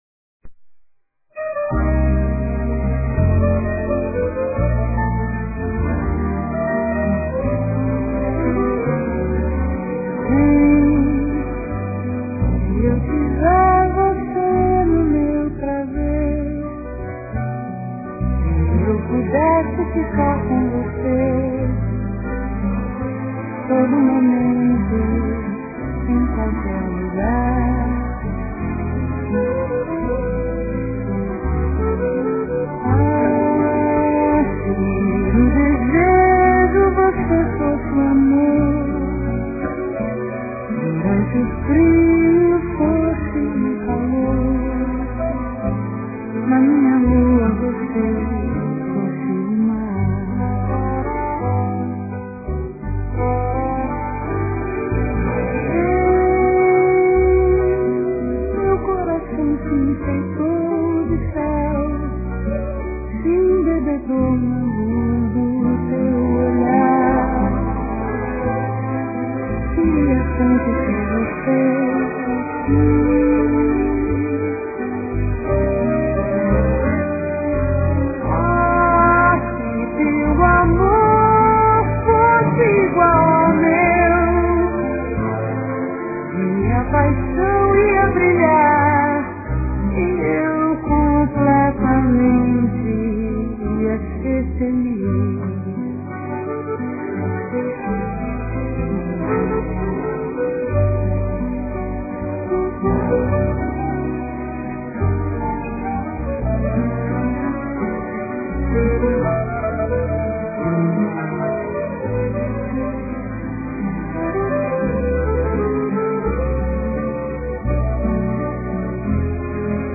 no final tem um Link para Abrir a Música que é Cantada.